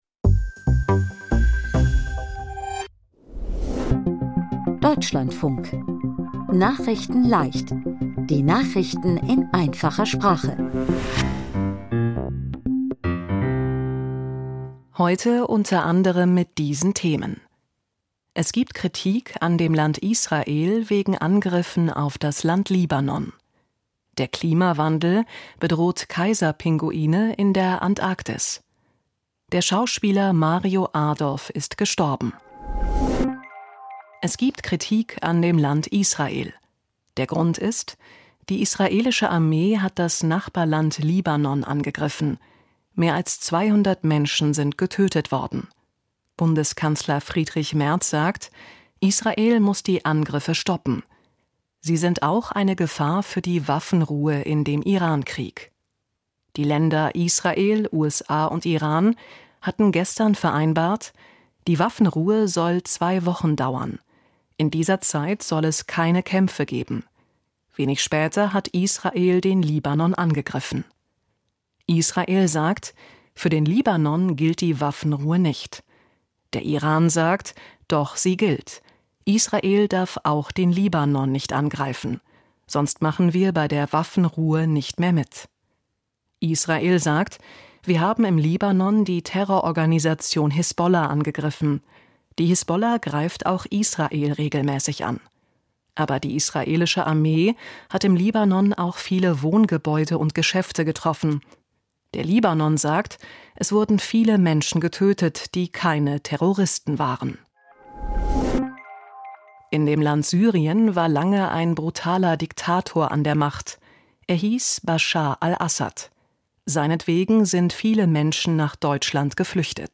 Nachrichtenleicht - Nachrichten in Einfacher Sprache vom 09.04.2026